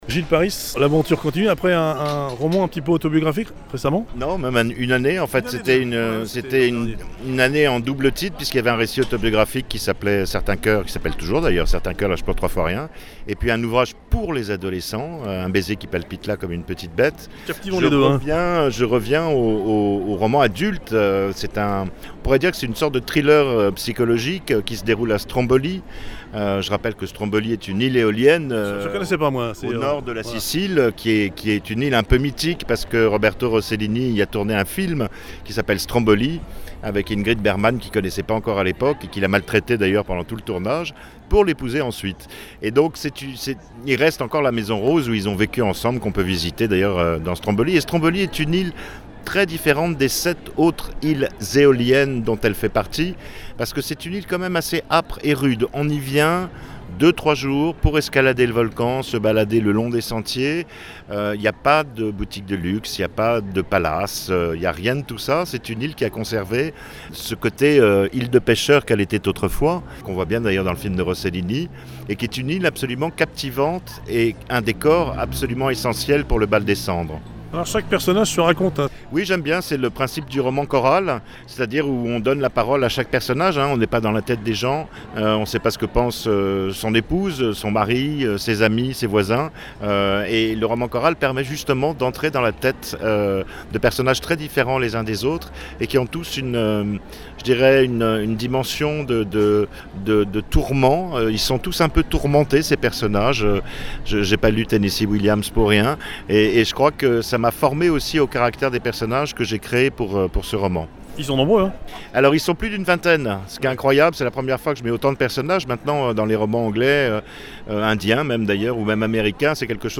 15e édition du Salon LIVR’A VANNES, à Vannes (Morbihan / Bretagne Sud)
Des auteurs en interviews :
GILLES PARIS  – Le Bal des Cendres (Plon) Interview
(diffusions Radio Korrigans, Radio Larg, Radio Balises…)